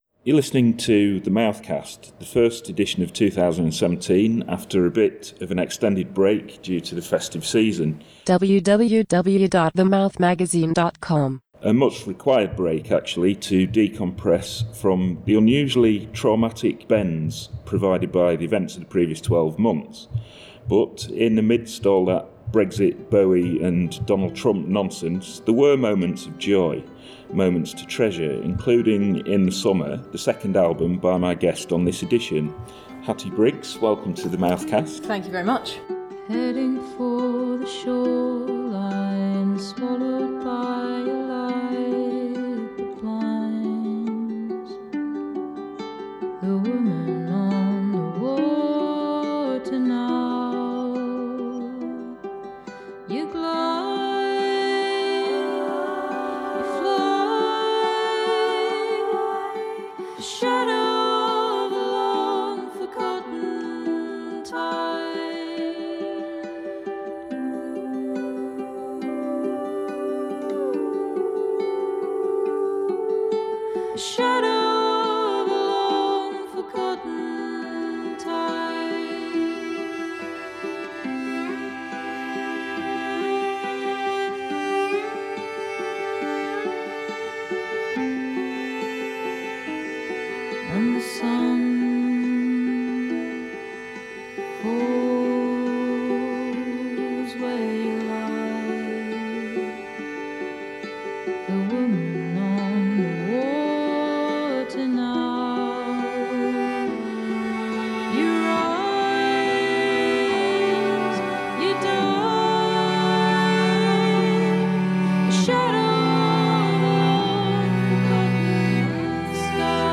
In this new edition of The Mouthcast, recorded between a recent soundcheck and show